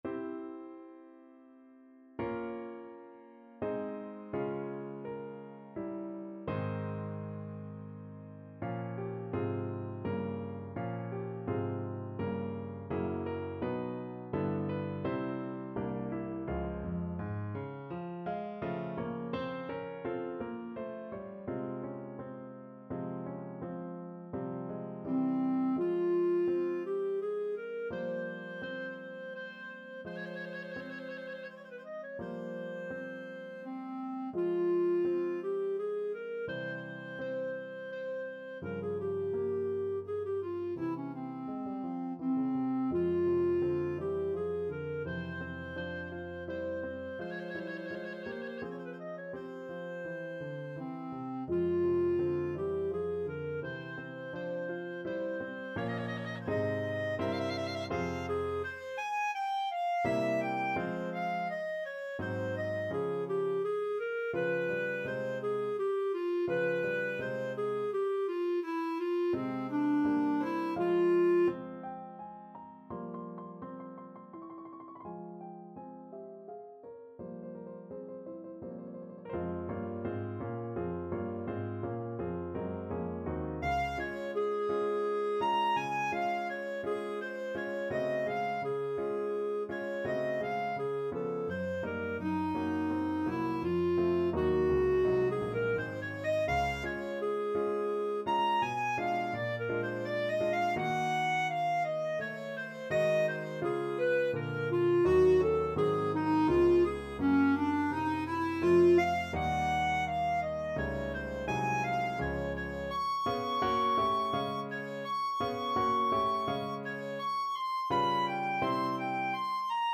3/4 (View more 3/4 Music)
Andante =84
Classical (View more Classical Clarinet Music)